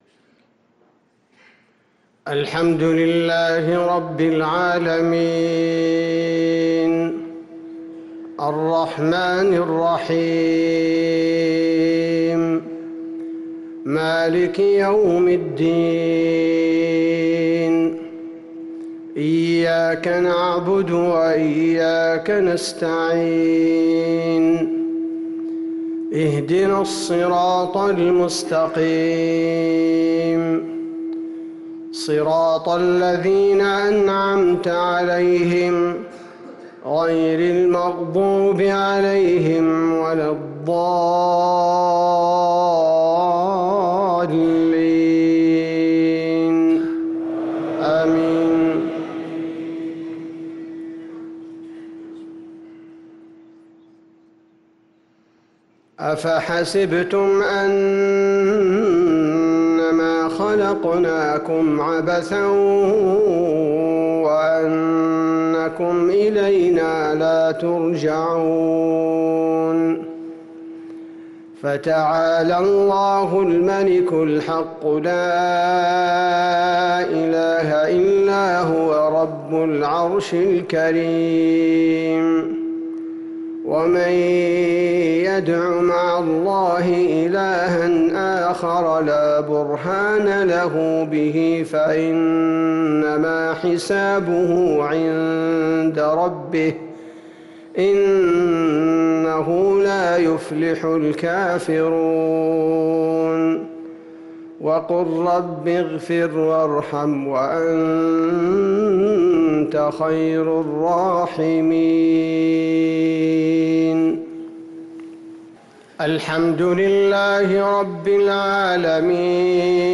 صلاة المغرب للقارئ عبدالباري الثبيتي 26 شوال 1444 هـ
تِلَاوَات الْحَرَمَيْن .